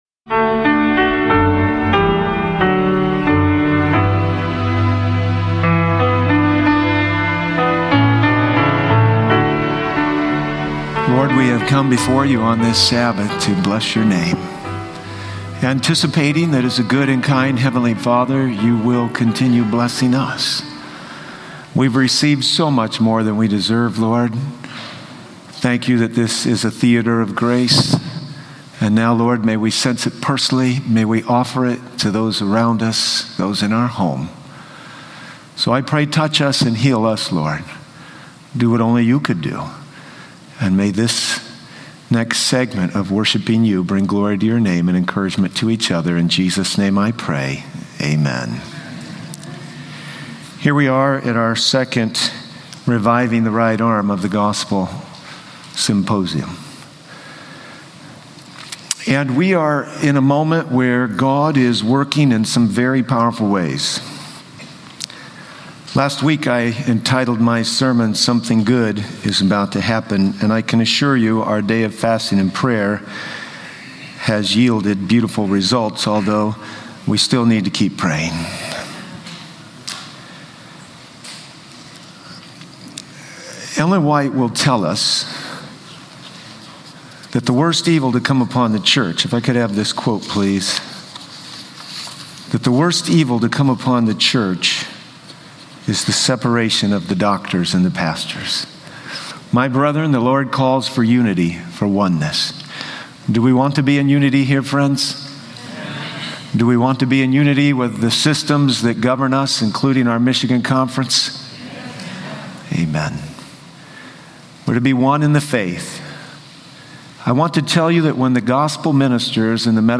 💡 Emphasis on Healing: The sermon addresses the healing power of Jesus, making it clear that healing extends beyond physical ailments to encompass forgiveness and emotional wellness.